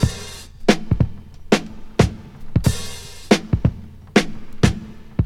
Free drum loop sample - kick tuned to the E note. Loudest frequency: 1302Hz
• 91 Bpm Drum Loop E Key.wav
91-bpm-drum-loop-e-key-vRt.wav